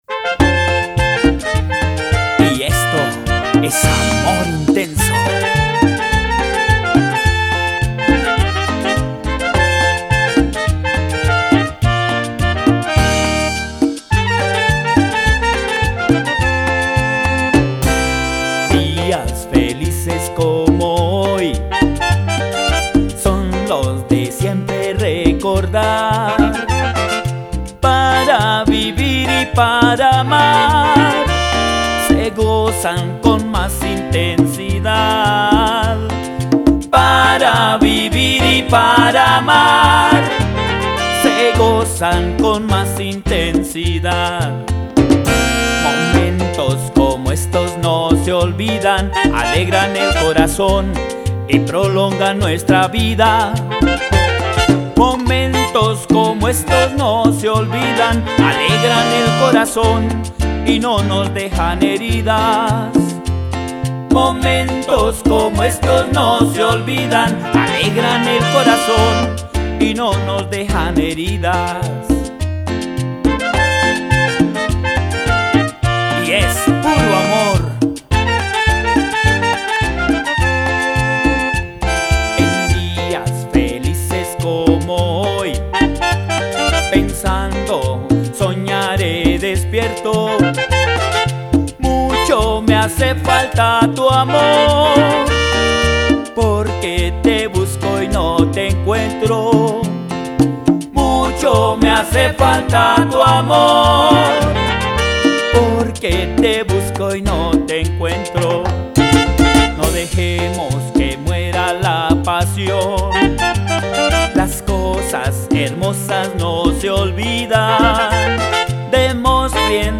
Tropical